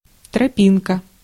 Ääntäminen
IPA: /pɑt/